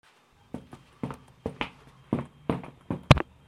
描述：pasos
Tag: pasos zapatos